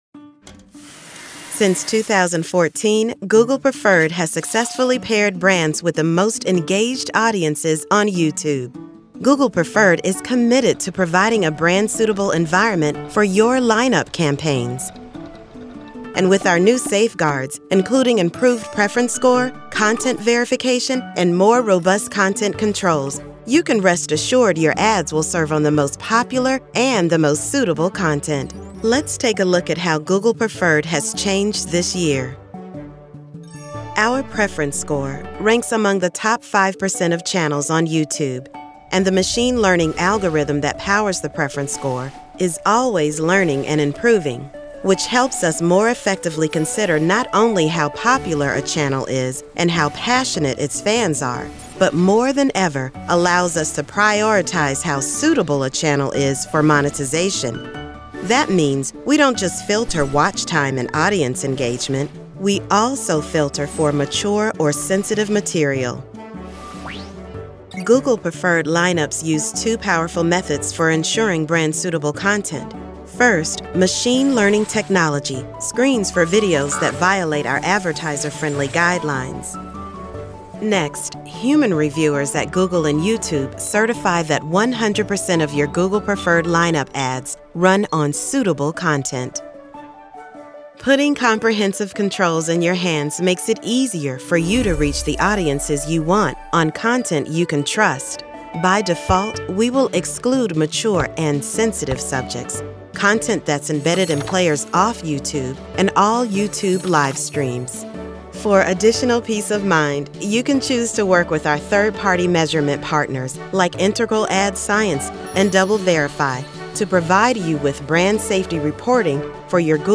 Inglés (Americano)
Llamativo, Seguro, Natural, Suave, Empresarial
Explicador
She works from an amazing home studio with professional equipment.